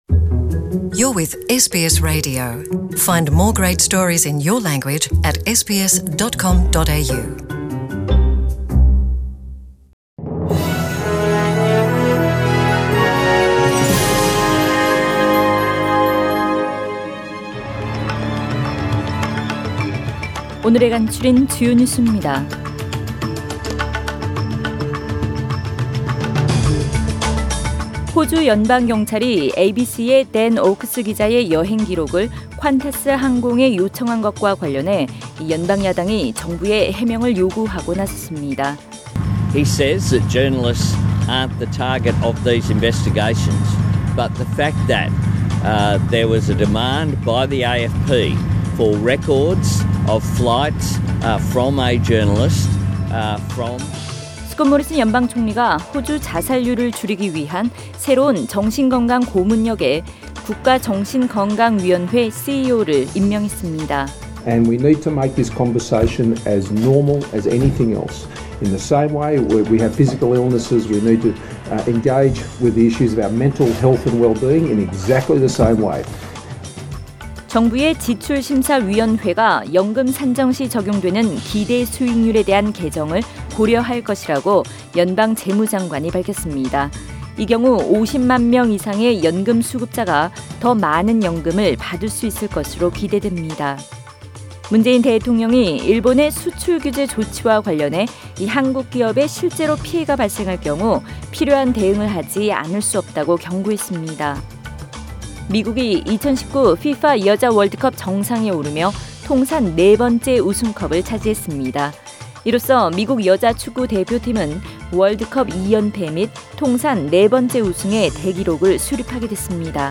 SBS 한국어 뉴스 간추린 주요 소식 – 7월 8일 월요일